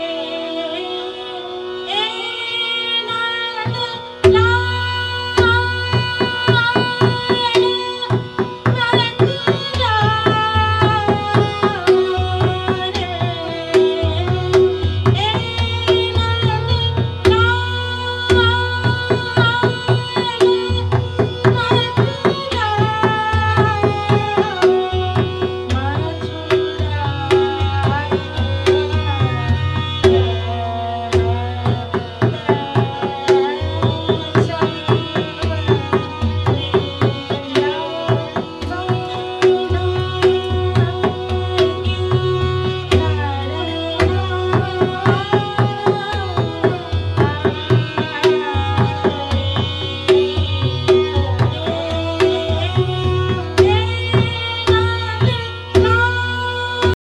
Typically, the asthayee spans from the tonic SA, ascending up to the Nishad (NI) of the middle octave, and then returns to the tonic, serving as an announcement for the subsequent antara section.
Asthayee_01.mp3